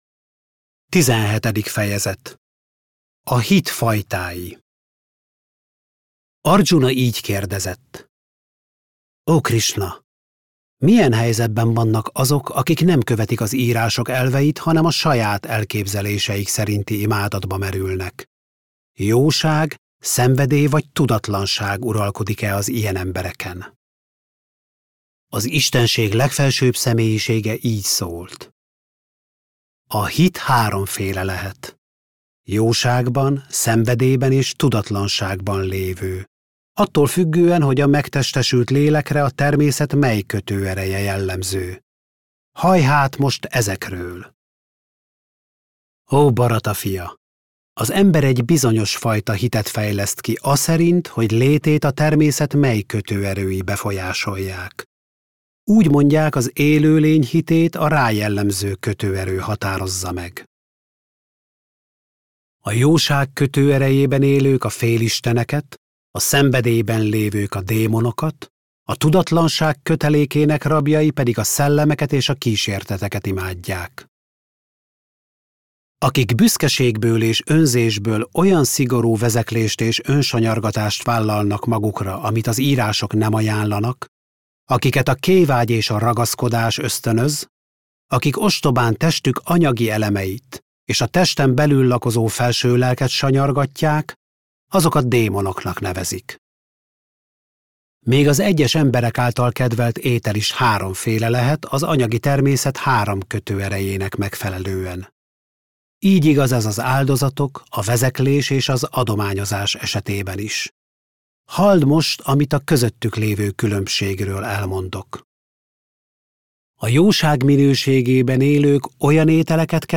Bhagavad-gītā hangoskönyv – Tizenhetedik fejezet - Magyarországi Krisna-tudatú Hívők Közössége